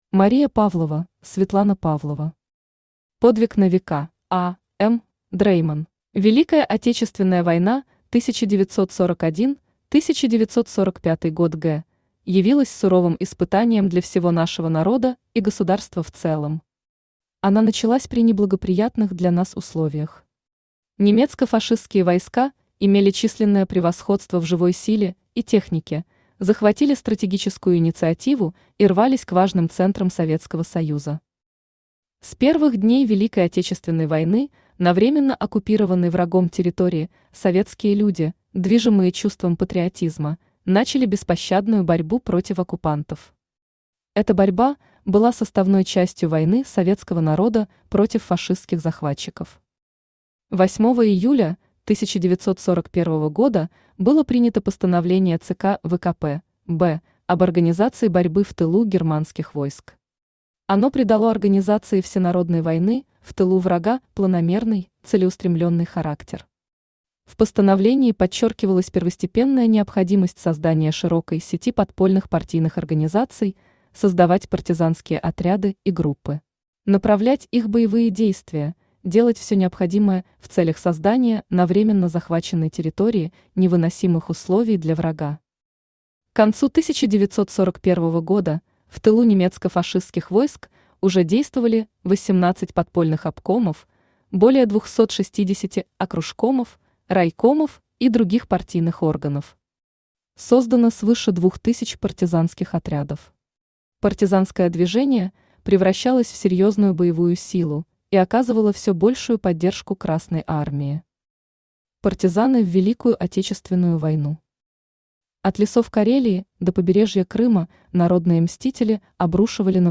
Читает: Авточтец ЛитРес
Аудиокнига «Подвиг на века: А.М. Дрейман».